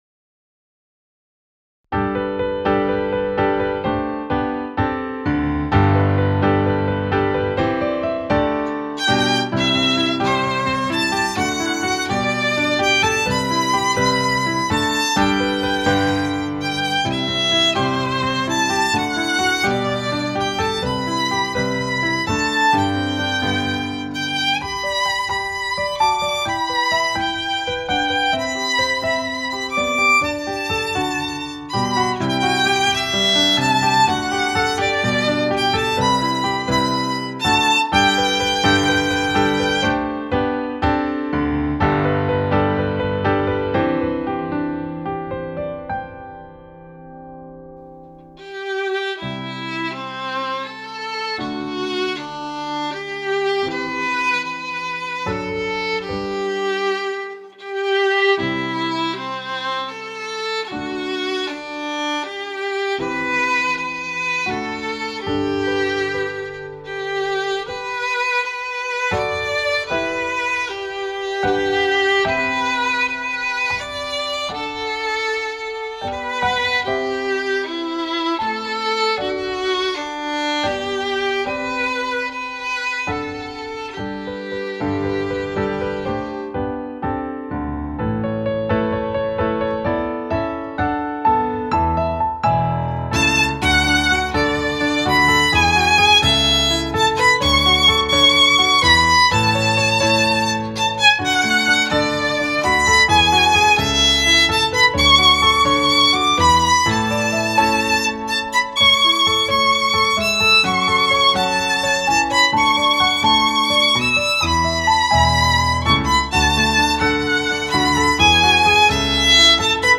Instruments: violin and piano